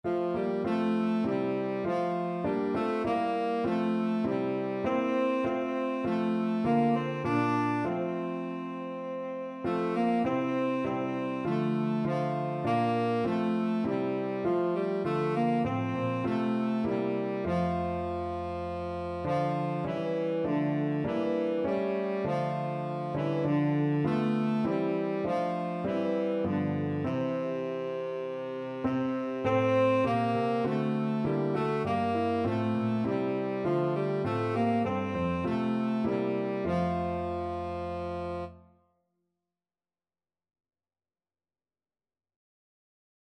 Christmas
4/4 (View more 4/4 Music)
C4-D5
Tenor Saxophone  (View more Easy Tenor Saxophone Music)
Classical (View more Classical Tenor Saxophone Music)